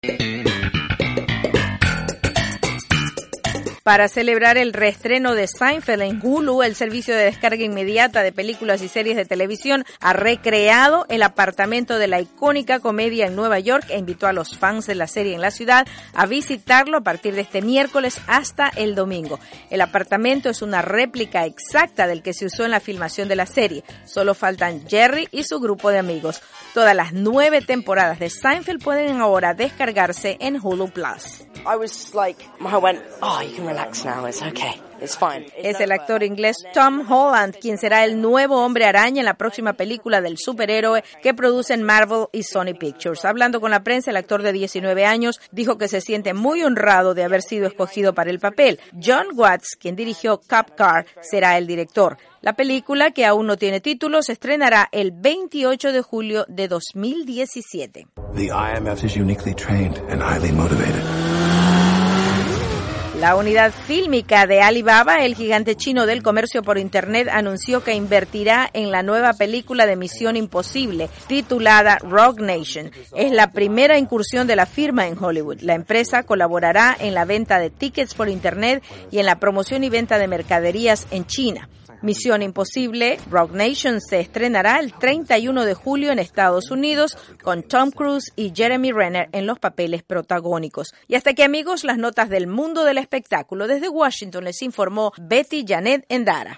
VOA: Noticias del Entretenimiento